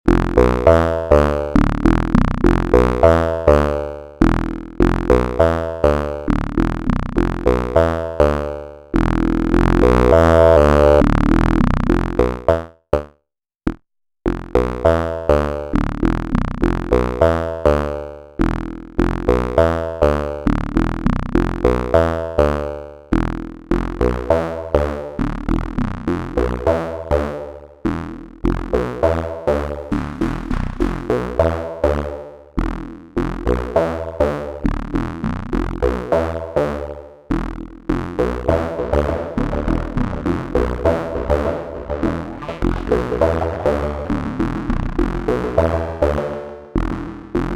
Here’s some direct audio examples of sliced WAV imports. DigiPRO sample playback on the Monomachine becomes way cleaner and more usable when we bypass slot normalisation and keep the original gain structure intact across a loop or single hit.
(1 drum hits, 2 break, 3 303, 4 break)